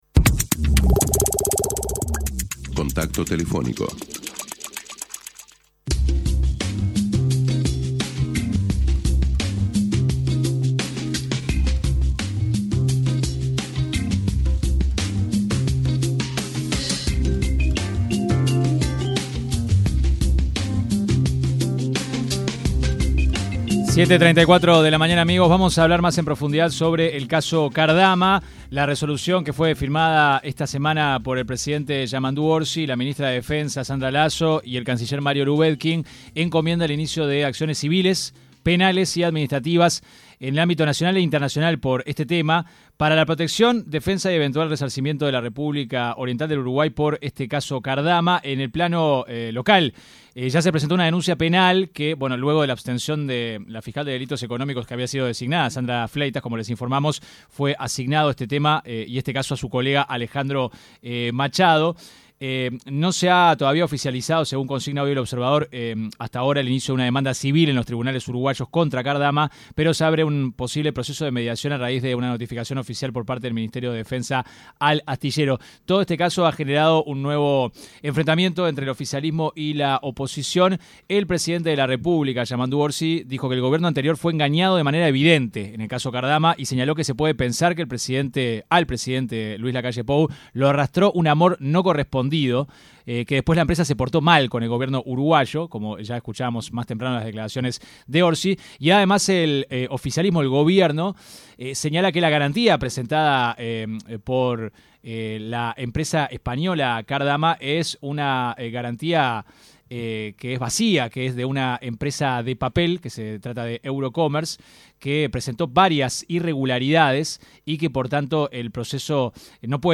El senador nacionalista y exministro de Defensa Nacional, Javier García, criticó en una entrevista con 970 Noticias, la decisión del Gobierno actual que busca rescindir el contrato con la empresa Cardama, encargada de la construcción de las patrulleras oceánicas, y señaló que los más beneficiados por esta acción, son el narcotráfico y el crimen organizado.